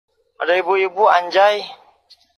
Efek suara Ada ibu ibu anjay
Kategori: Suara viral